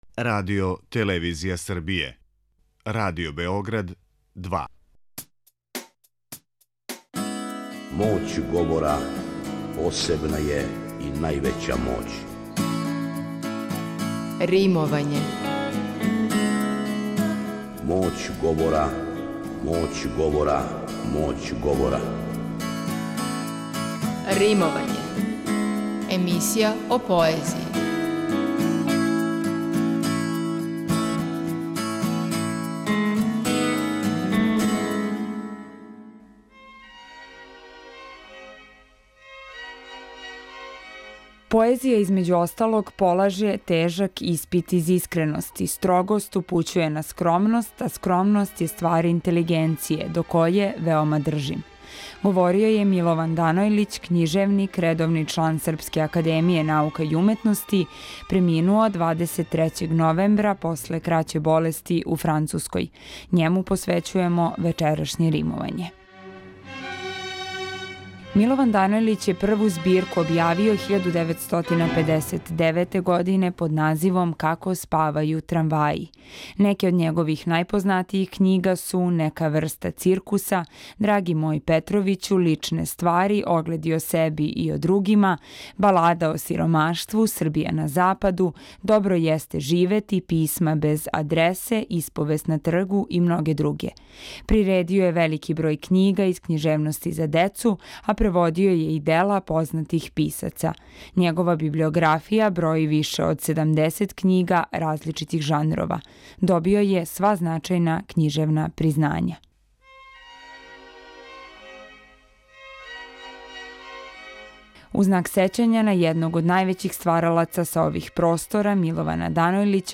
Римовање посвећујемо Данојлићевом непролазном песничком делу, подсећајући се његове вечери одржане у оквиру циклуса Пoезија уживо! Радио Београда 2.
преузми : 90.73 MB Римовање Autor: Група аутора У новој емисији посвећеној поезији, слушаоци ће моћи да чују избор стихова из Звучног архива Радио Београда које говоре најчувенији домаћи и инострани песници, драмски уобличене поетске емисије из некадашње серије „Вртови поезије", као и савремено стваралаштво младих и песника средње генерације.